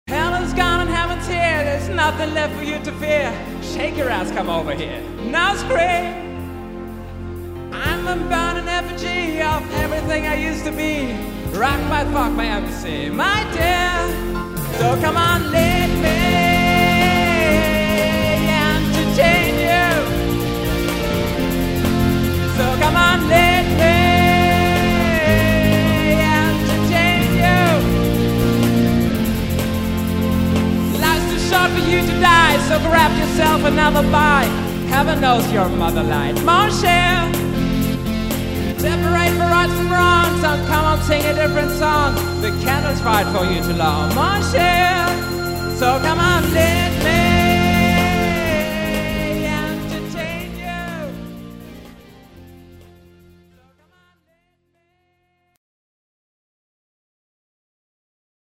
Piano & Vocals (am Keyboard und Klavier)
Anheizend: